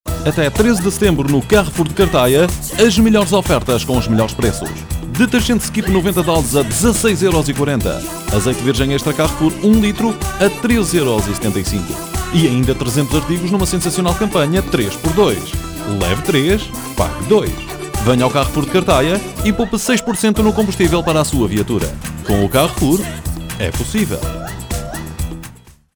Cuña en portugués para la empresa Carrefour.